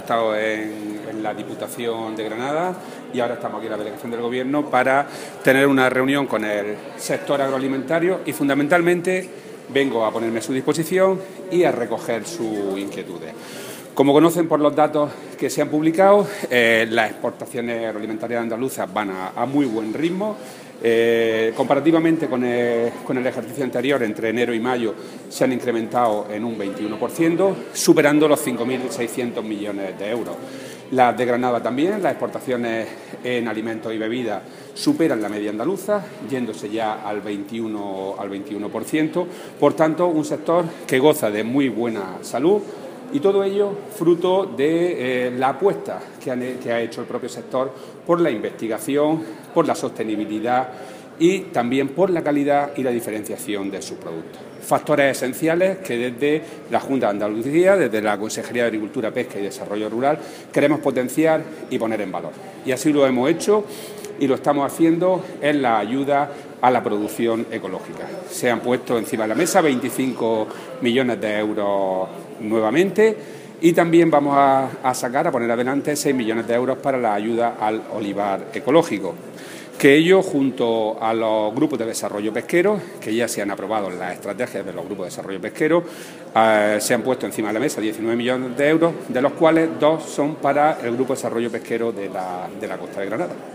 Declaraciones de Rodrigo Sánchez sobre la Mesa Agroalimentaria de Granada